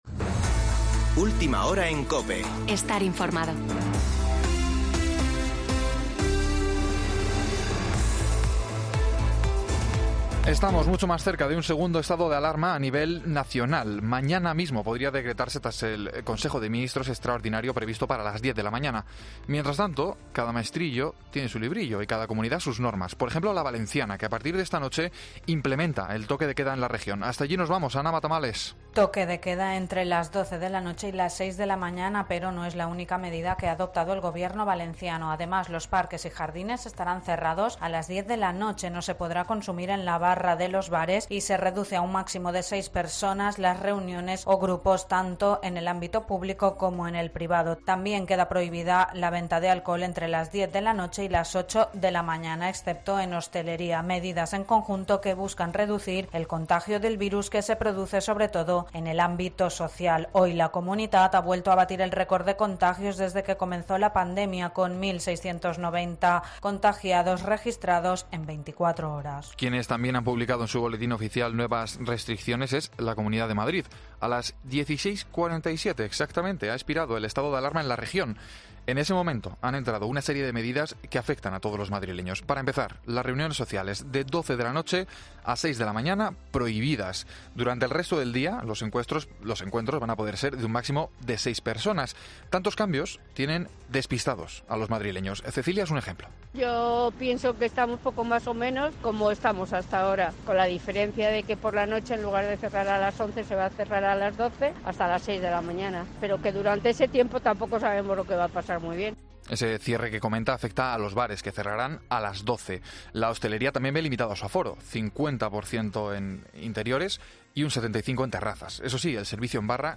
AUDIO: Boletín de noticias de COPE del 24 de Octubre de 2020 a las 19.00 horas